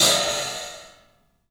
Index of /90_sSampleCDs/E-MU Producer Series Vol. 5 – 3-D Audio Collection/3DPercussives/3DPAHat